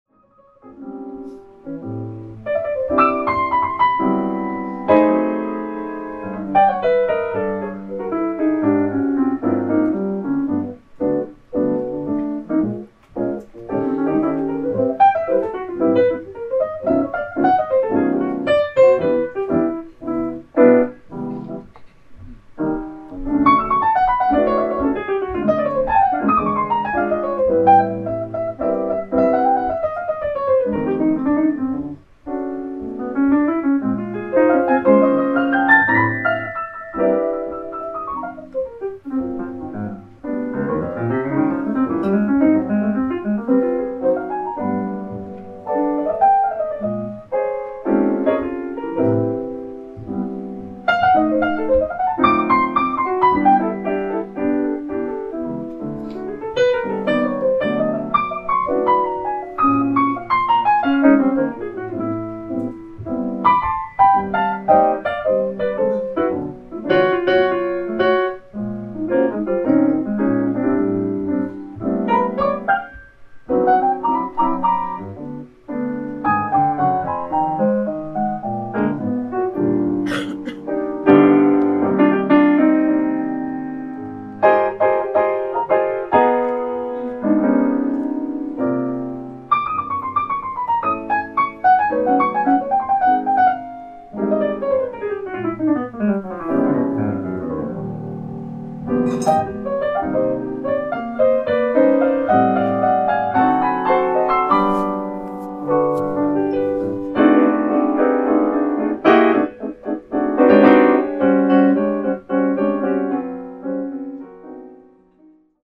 ライブ・アット・ザ・ブルーノート、ニューヨーク、NY 11/18/2016
新マスターからノイズレスにてお聴き頂けます！！
※試聴用に実際より音質を落としています。